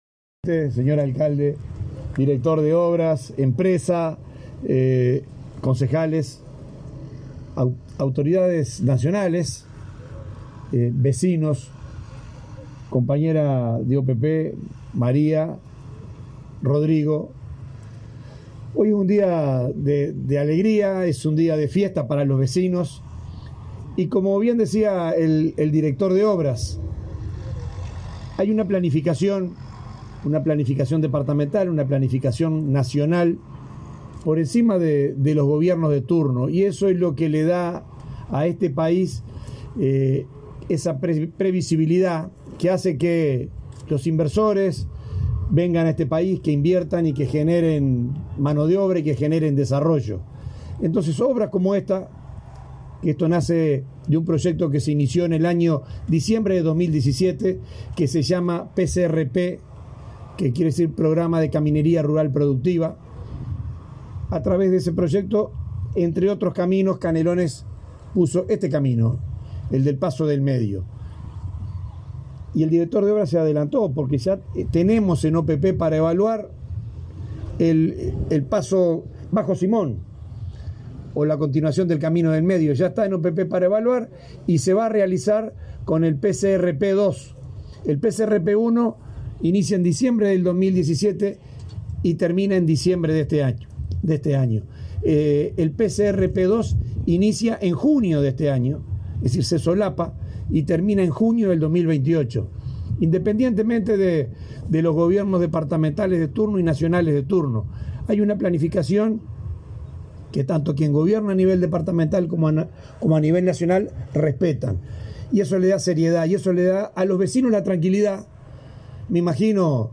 Palabras del subdirector de la OPP, Benjamín Irazabal
Palabras del subdirector de la OPP, Benjamín Irazabal 28/02/2023 Compartir Facebook X Copiar enlace WhatsApp LinkedIn Con la presencia del subdirector de la Oficina de Planeamiento y Presupuesto (OPP), Benjamín Irazabal, se inauguró, este 27 de febrero, una obra de pavimentación en el camino Paso del Medio, Canelón Chico, en la ciudad de Las Piedras.